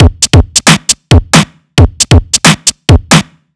Overload Break 2 135.wav